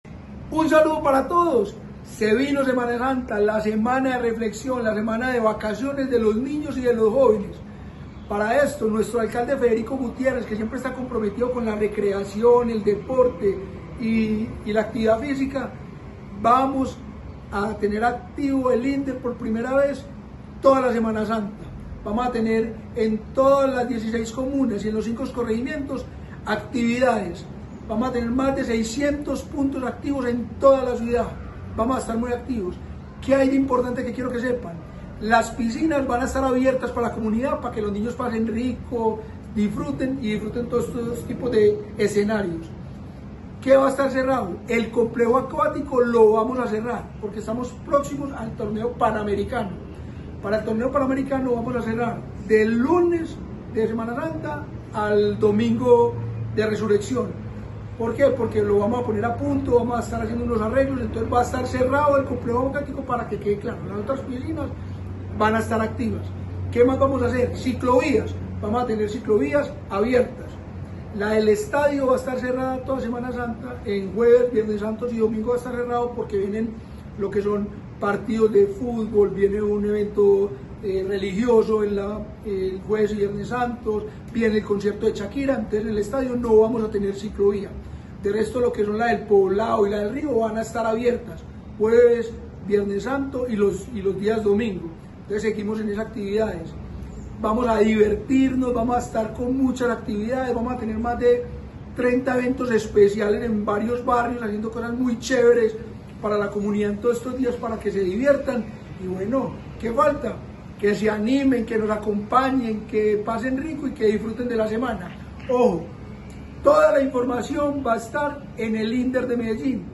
Palabras-de-director-del-Inder-Eduardo-Silva-Meluk.mp3